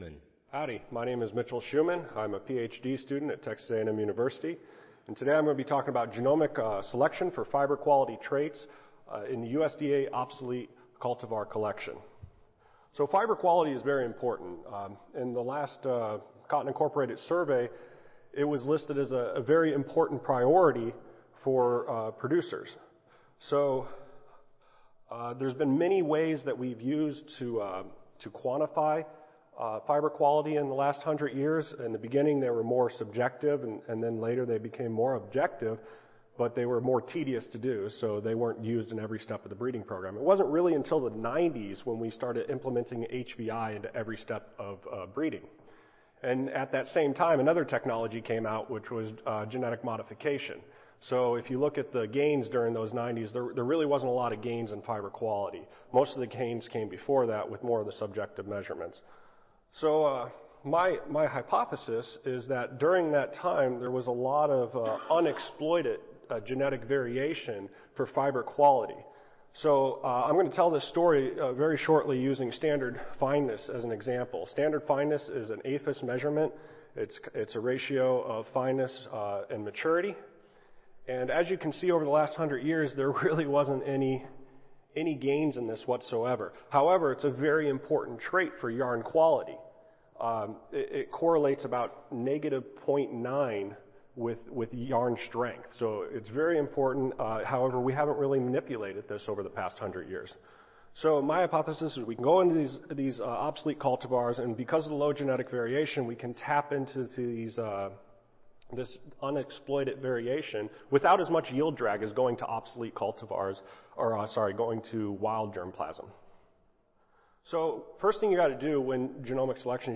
Cotton Improvement - Lightning Talk Student Competition
Audio File Recorded Presentation